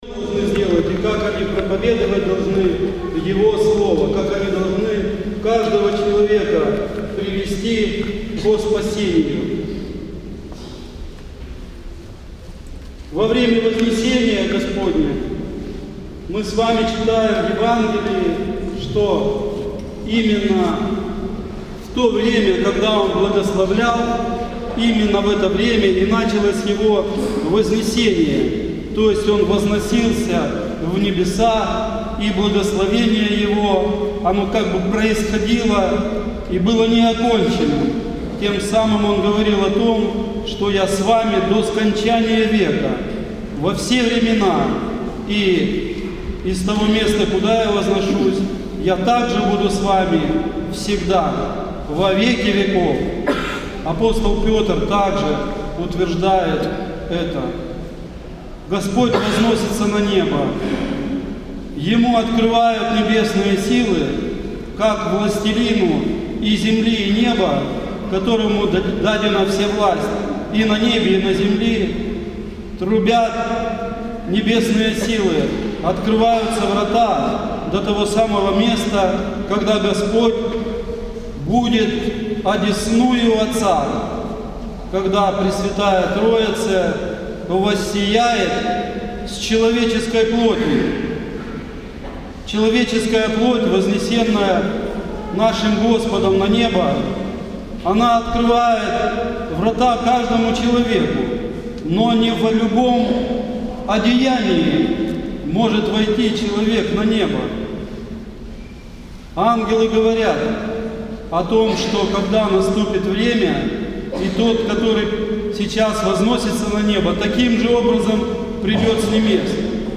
Проповедь на Вознесение Господне